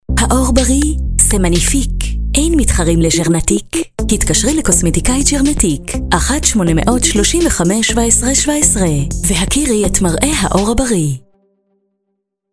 דוגמאות קריינות לפרסומות – אאודיו